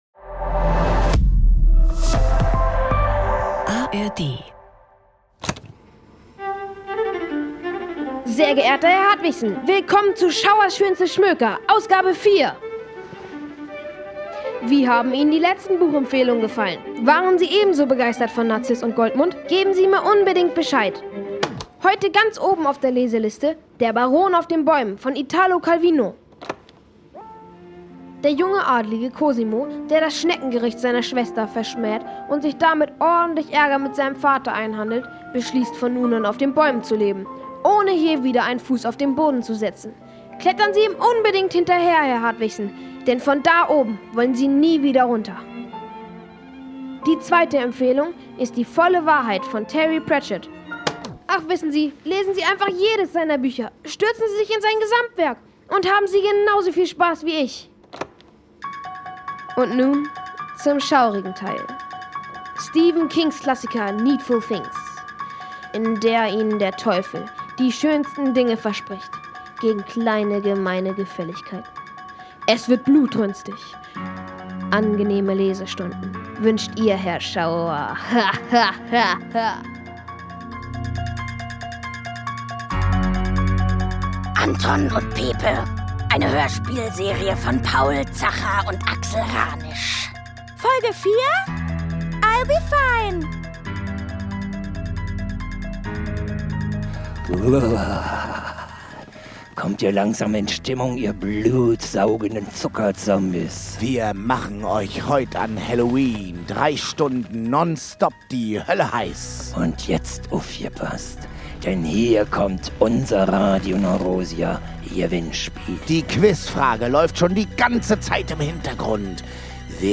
Hörspielserie